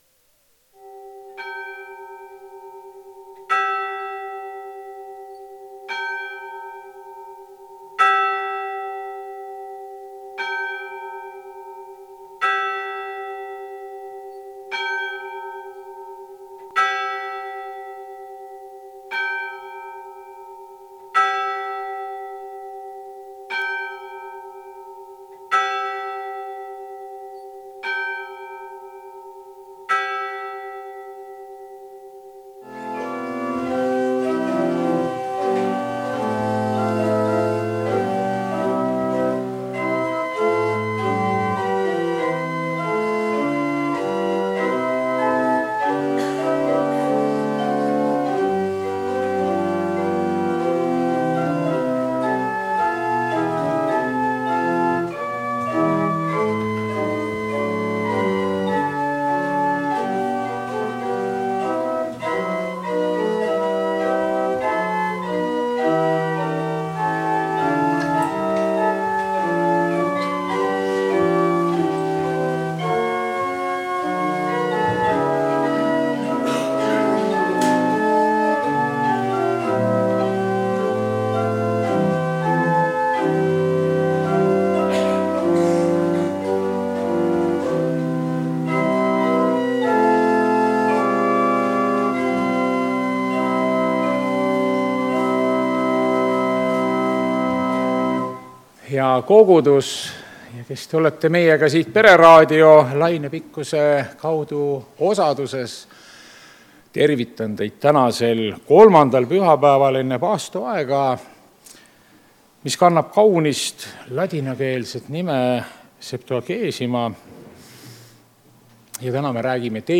Jumalateenistus 1. veebruar 2026